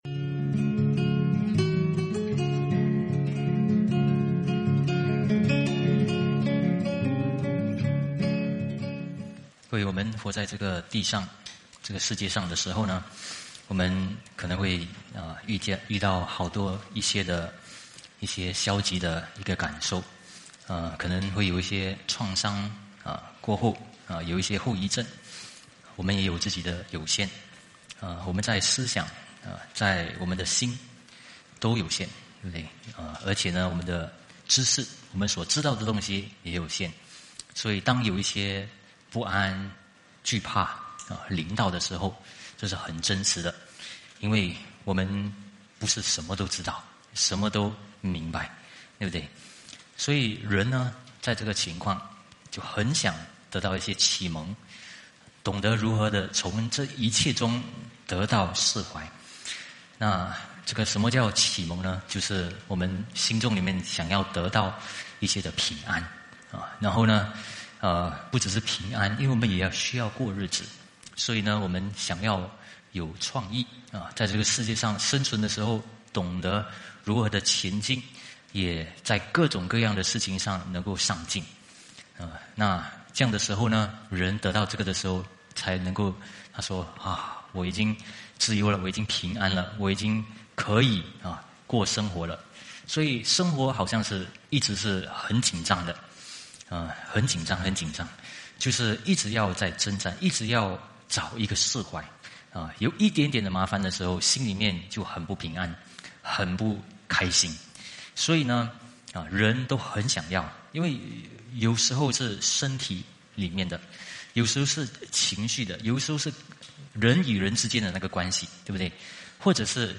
主日信息